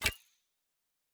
Fantasy Interface Sounds
Weapon UI 10.wav